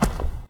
pedology_silt_footstep.4.ogg